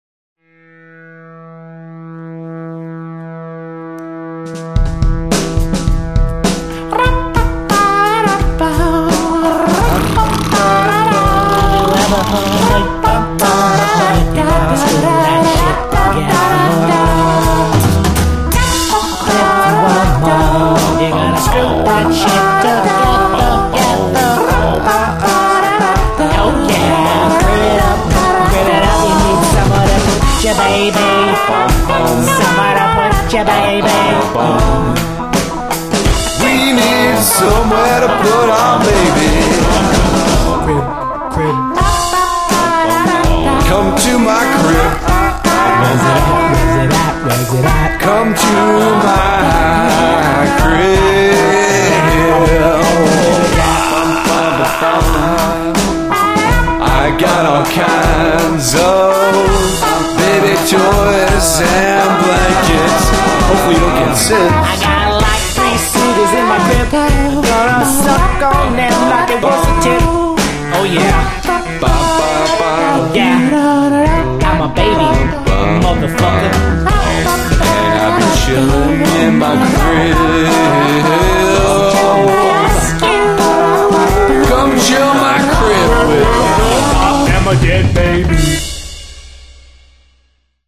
Experimental
Main Vocals
Drums
Backup Vocals
Synthesizer